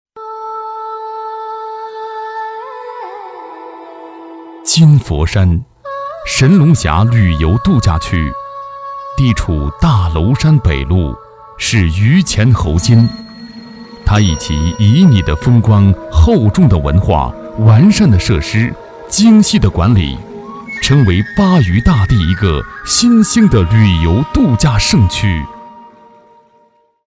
男国191_宣传片_旅游_神龙峡旅游度假区_低沉.mp3